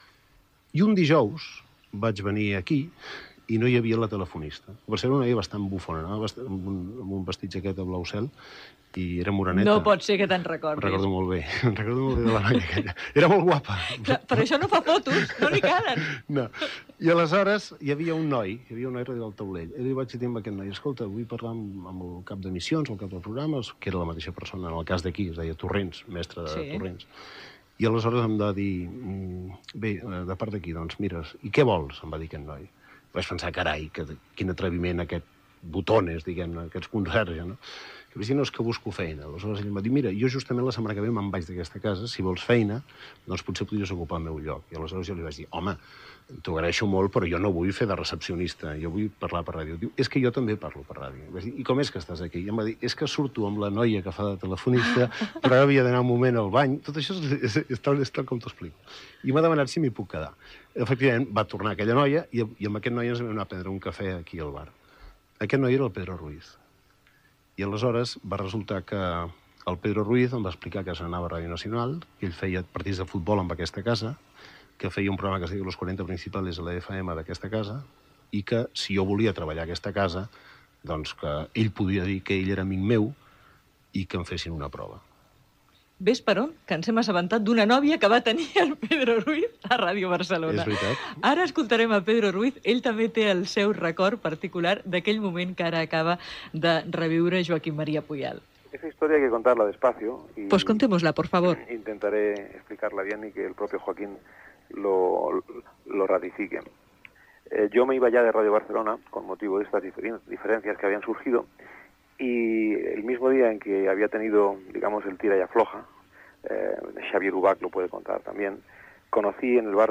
Joaquím Maria Puyal explica una anècdota de quan va demanar feina a Ràdio Barcelona, relacionada amb Pedro Ruiz. Paraules de Pedro Ruiz sobre com va començar a treballar Puyal a Ràdio Barcelona.
Divulgació